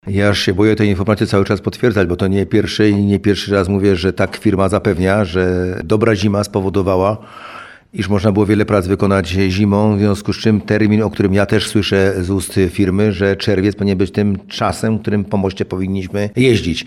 Teraz informację tę potwierdził starosta bielski Andrzej Płonka.